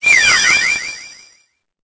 Cri_0857_EB.ogg